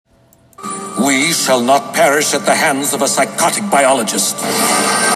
From X-Men: The Animated Series.